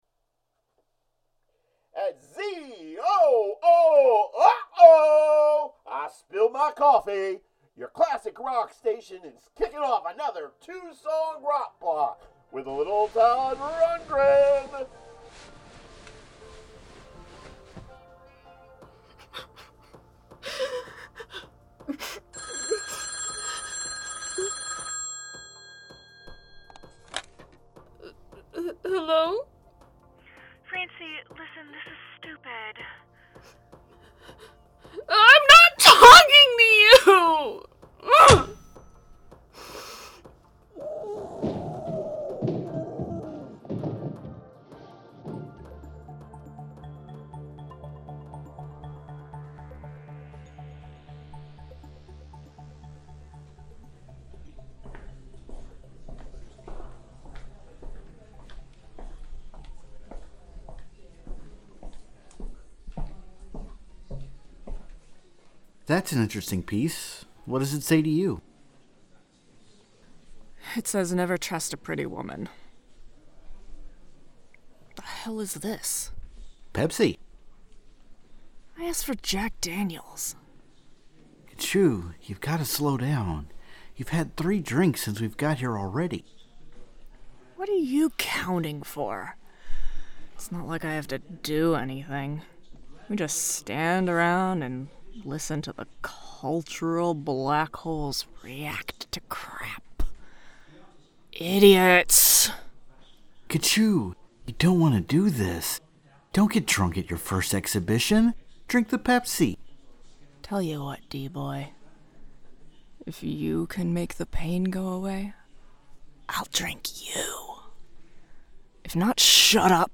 Strangers In Paradise – The Audio Drama – Book 7 – Episode 8 – Two True Freaks